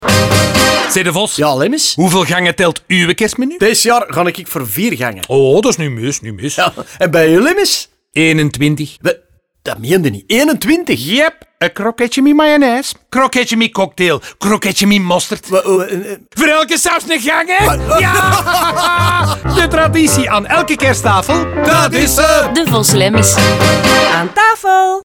In de nieuwe radiospots komen ze smakelijk aan bod.
DevosLemmens_NL25s_Gangen_Radio.mp3